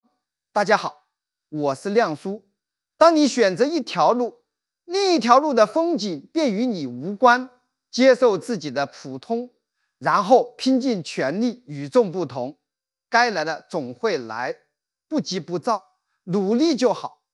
最近雷军AI配音火出圈，一键免费生成！保姆级教程分享！
演示：
这两天被雷军这个AI配音刷屏了，在某音，B站上大火！